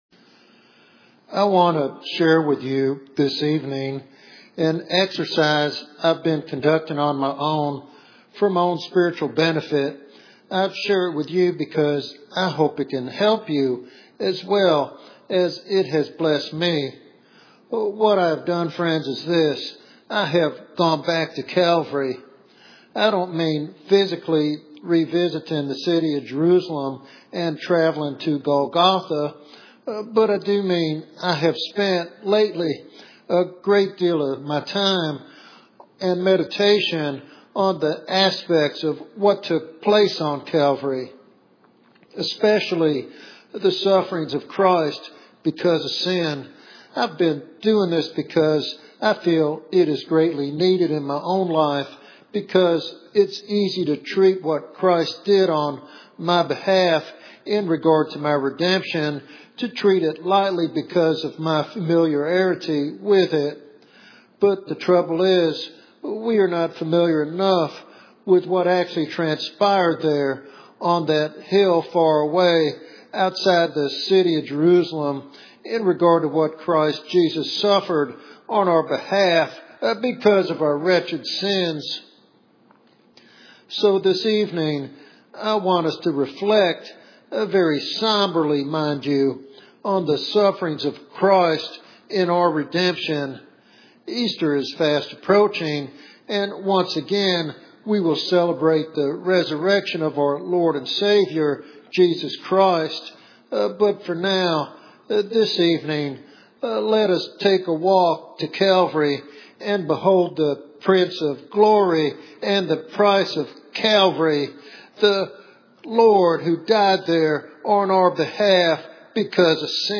This devotional message encourages a renewed appreciation for Christ’s sacrifice and a transformed walk with God.